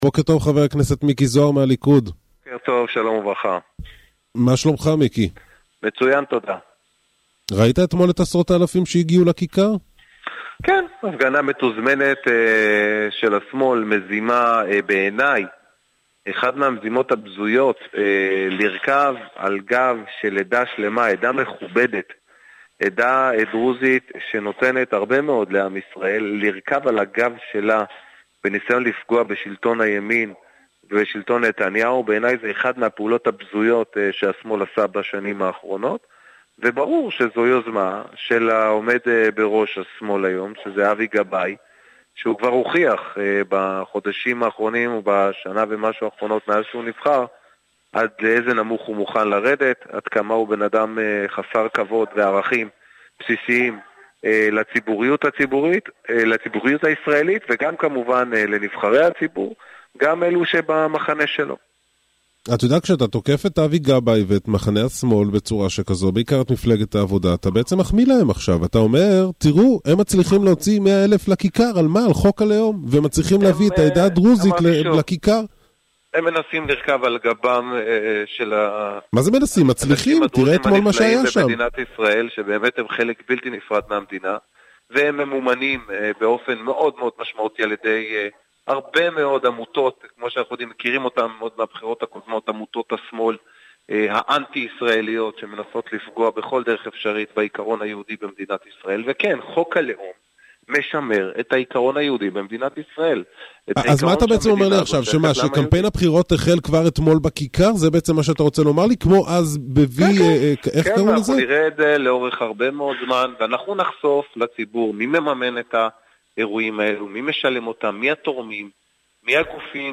Полное аудиоинтервью с Зоаром можно послушать здесь .